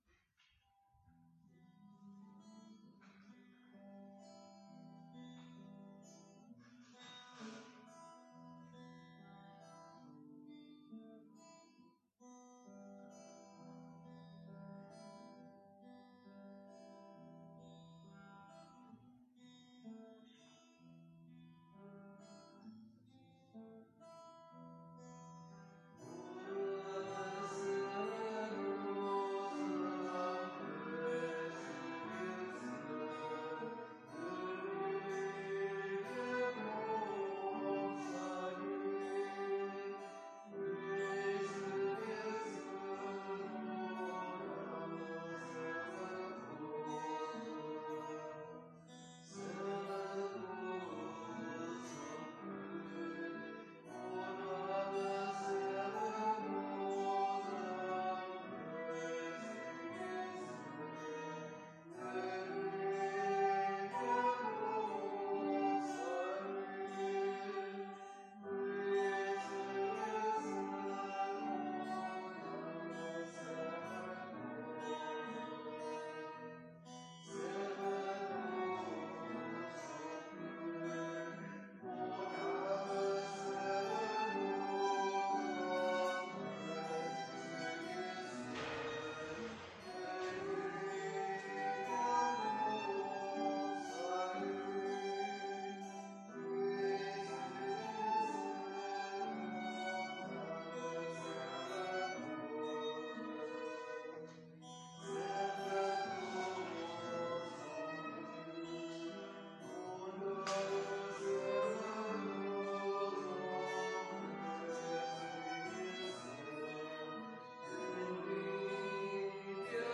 Pregària de Taizé a Mataró... des de febrer de 2001
Parròquia de la Mare de Déu de Montserrat - Diumenge 23 de febrer de 2020